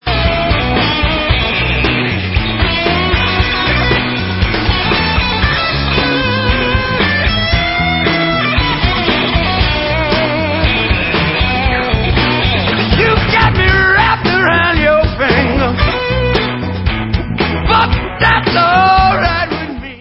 New studio album